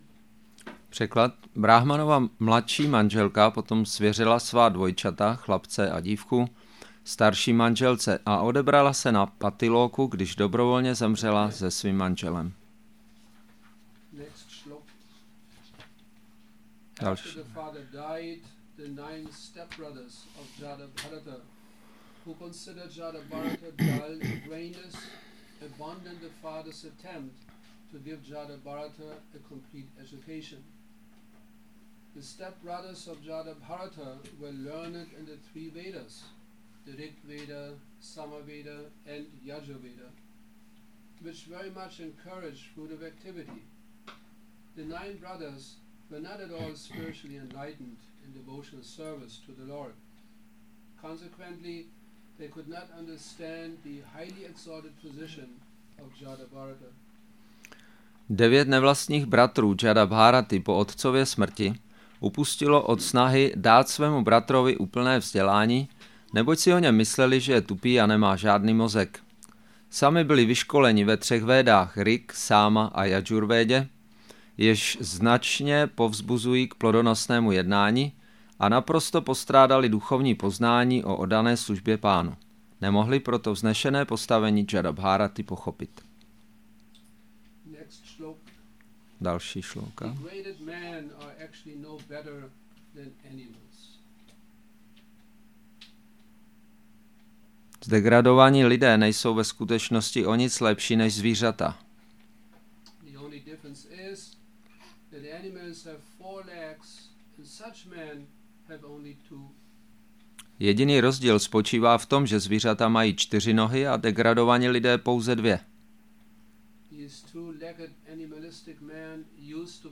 Přednáška SB-5.9.7 – Šrí Šrí Nitái Navadvípačandra mandir